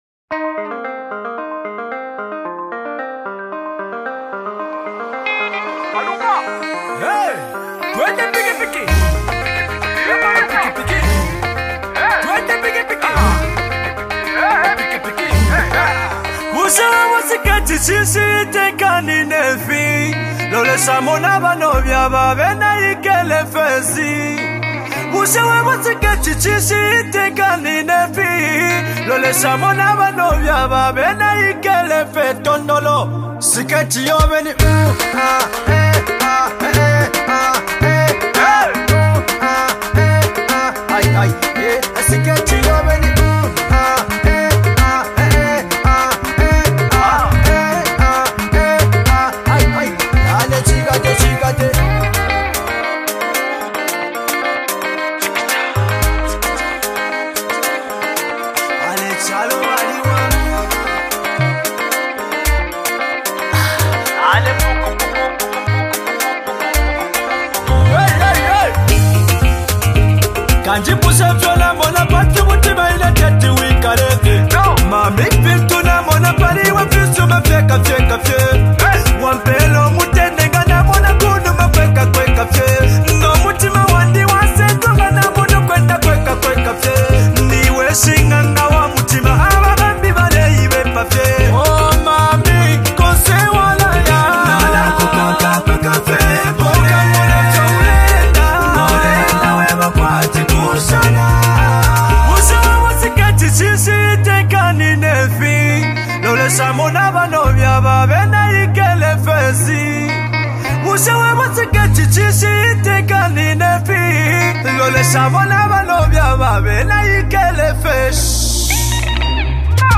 Kalindula
its for the club and love people